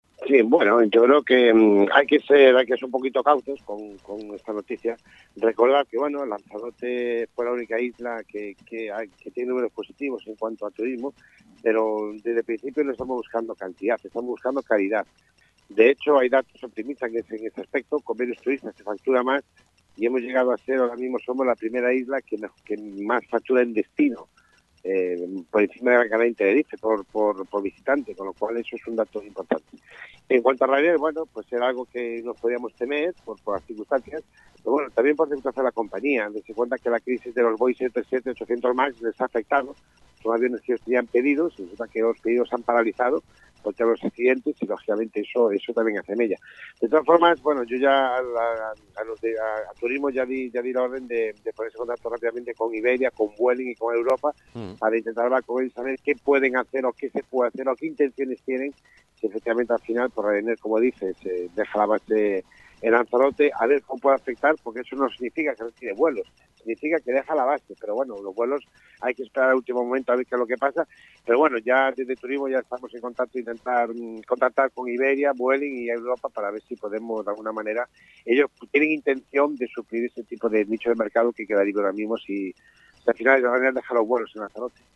Tal y como ya adelantó Vázquez este lunes en la entrevista concedida al programa 'A buena hora' de Crónicas Radio-COPE Lanzarote, el consejero ha confirmado que “aunque todavía es pronto para saber exactamente en qué medida esta decisión afectará a los vuelos, rutas y frecuencias que mantiene la aerolínea con el aeropuerto César Manrique-Lanzarote, queremos reunirnos con representantes de Iberia, Air Europa, easyJet, Vueling, Jet2, Volotea, Eurowings y Norwegian, entre otras, para explorar opciones y reforzar la conectividad con la isla”, señala Ángel Vázquez, quien a su vez puntualiza que “una cosa es que Ryanair cierre su base y otra que vaya a dejar de operar con Lanzarote”.